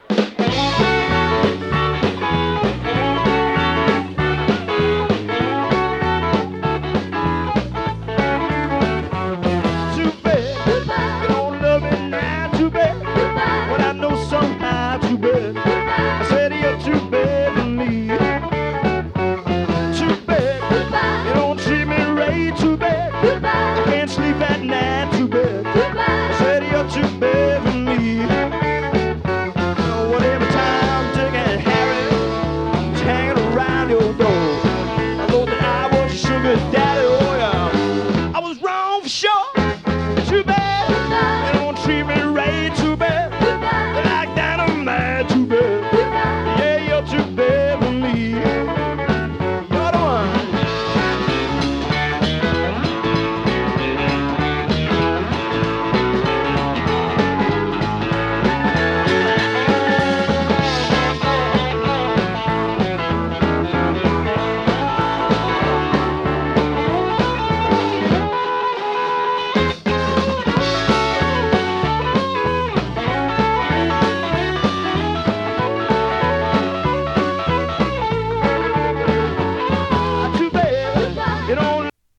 豪州ジャイヴ ネオロカ